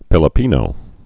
(pĭlə-pēnō)